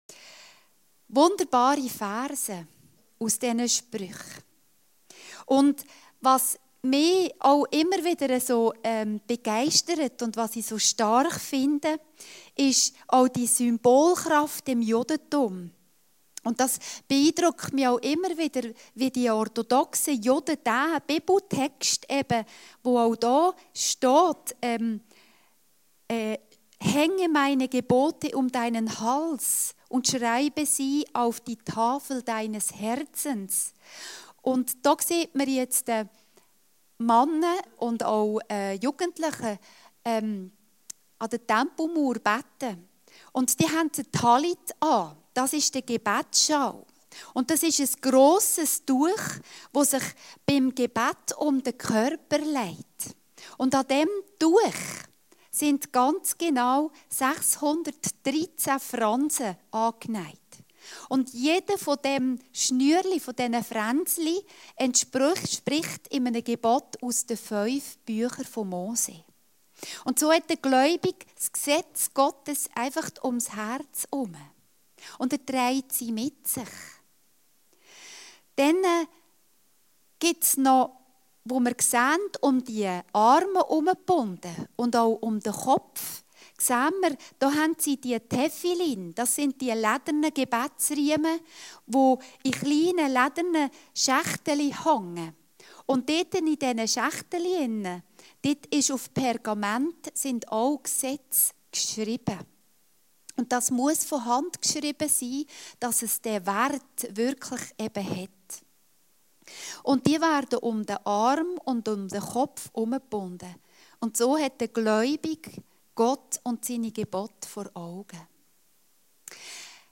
Predigten Heilsarmee Aargau Süd – Gnade und Treue sollen dich nicht verlassen